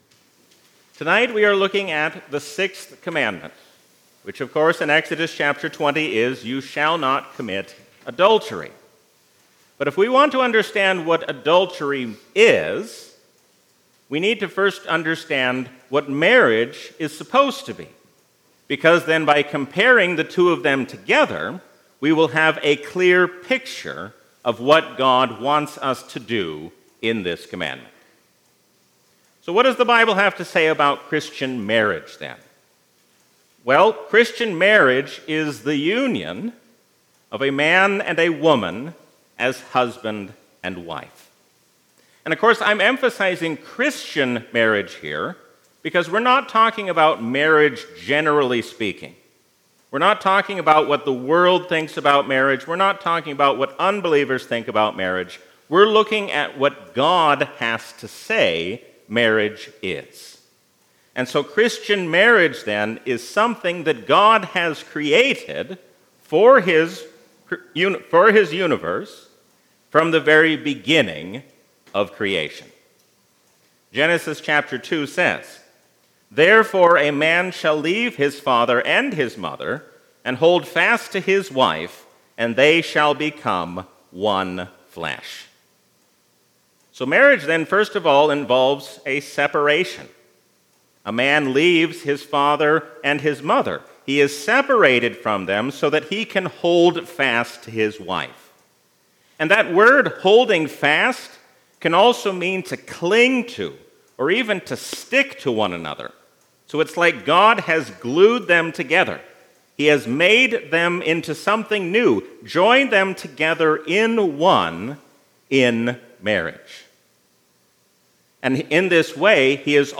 A sermon from the season "Gesimatide 2023."